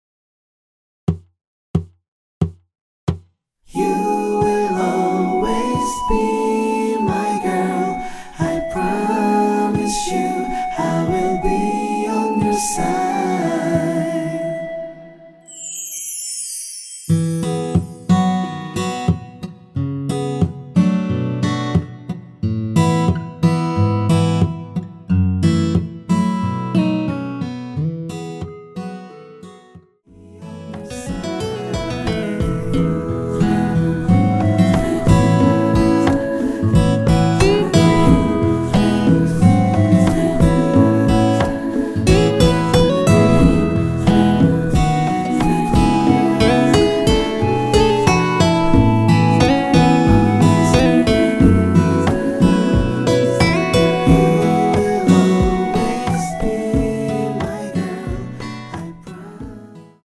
전주 없이 무반주로 노래가 시작 하는 곡이라서
노래 들어가기 쉽게 전주 1마디 넣었습니다.(미리듣기 확인)
원키 멜로디와 코러스 포함된 MR입니다.
앞부분30초, 뒷부분30초씩 편집해서 올려 드리고 있습니다.